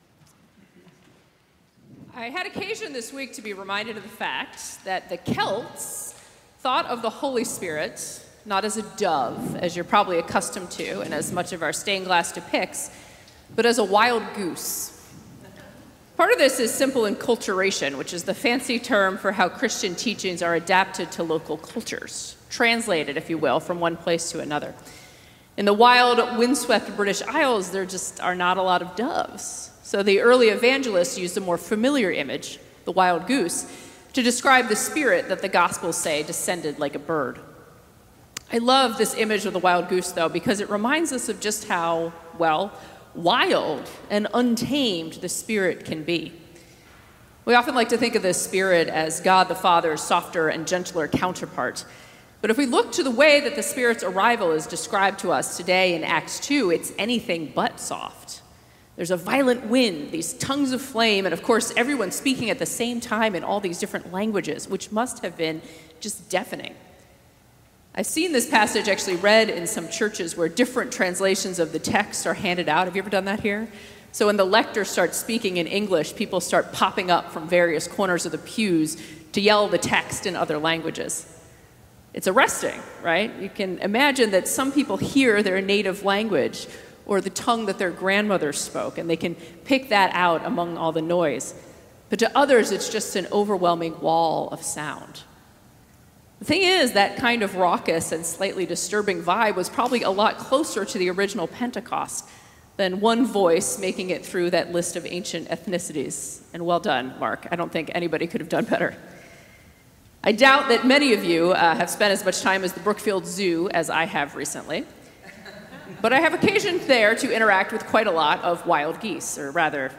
Sermons | Emmanuel Episcopal Church